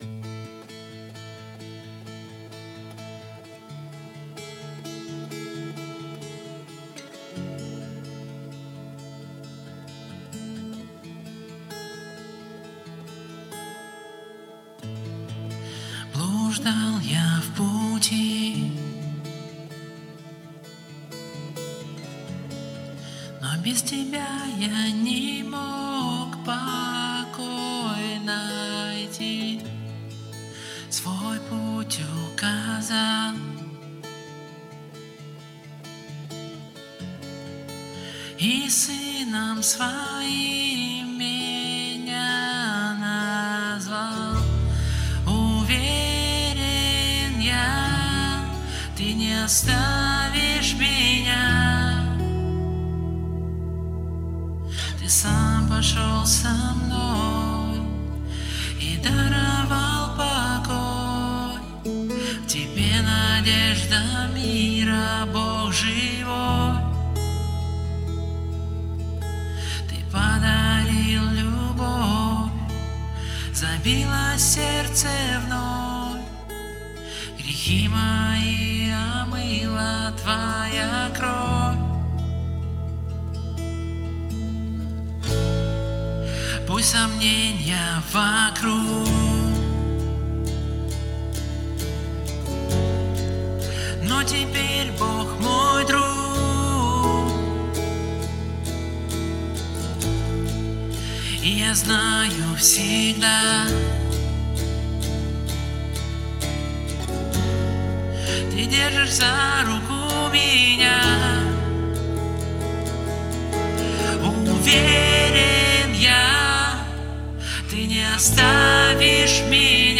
391 просмотр 84 прослушивания 7 скачиваний BPM: 140